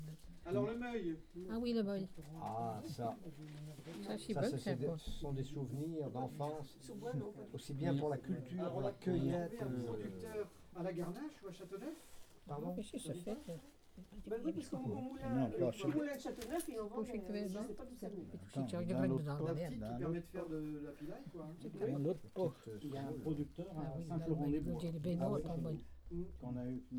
Atelier de collectage de témoignages sur les légumes traditionnels du Marais-Breton-Vendéen
Témoignage